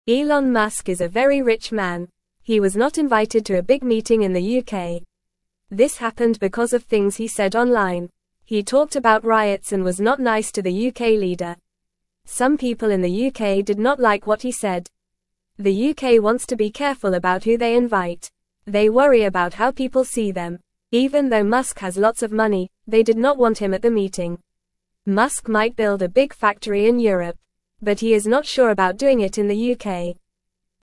Fast
English-Newsroom-Beginner-FAST-Reading-Elon-Musk-not-invited-to-UK-meeting-upset.mp3